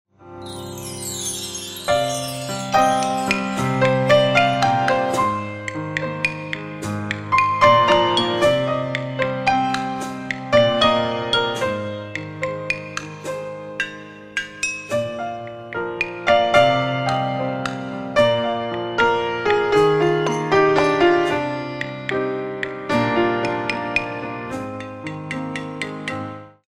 The Original PIANO Compositions
good for ballet and modern lyrical